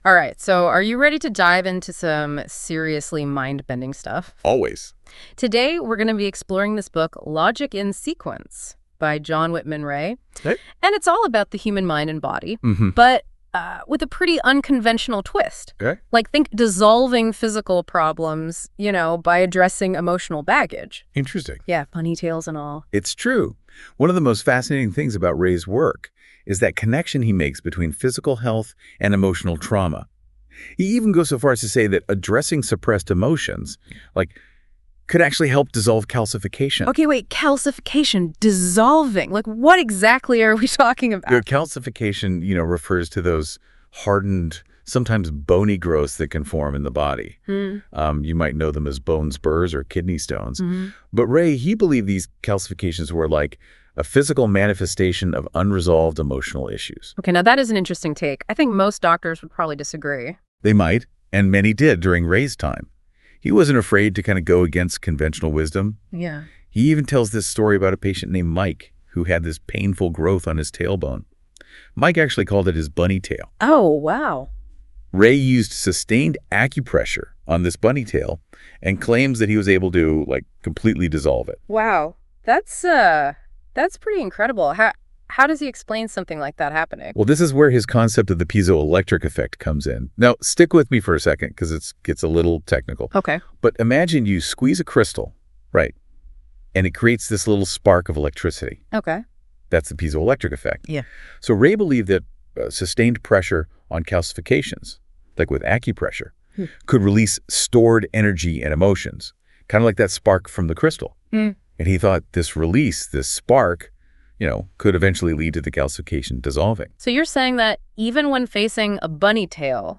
Here’s an AI conversation about it.